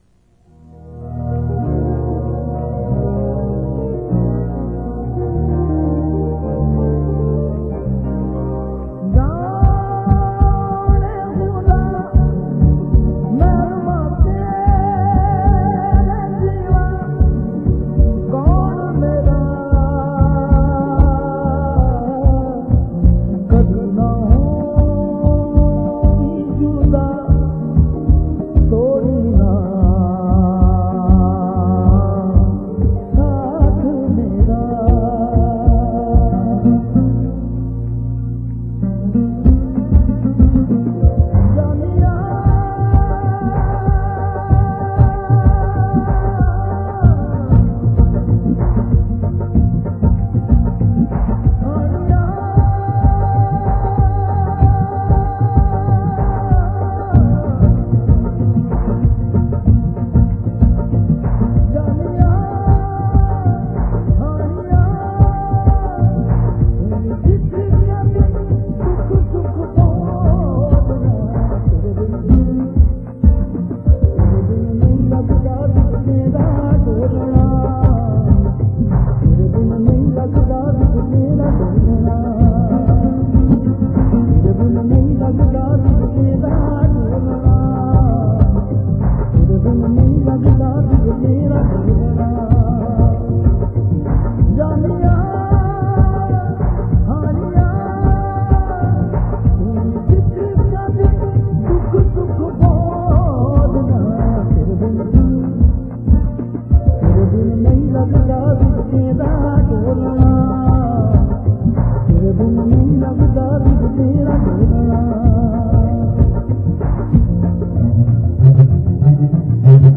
Punjabi qawwali in the folk-devotional register